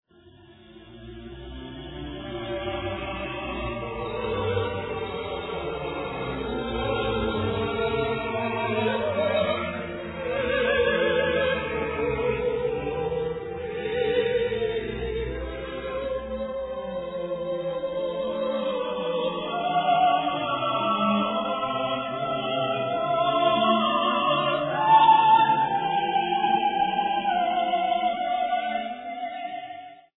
Solo Quartet, Chorus and Orchestra
9 SOLO QUARTET: